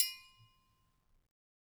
Triangle6-HitFM_v1_rr1_Sum.wav